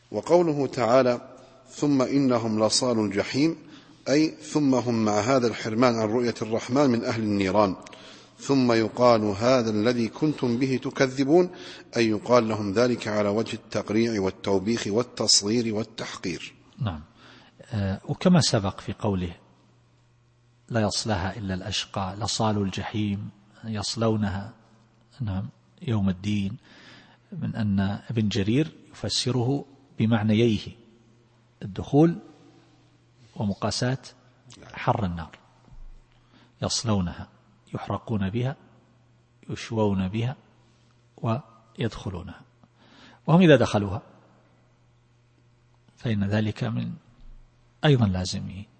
التفسير الصوتي [المطففين / 17]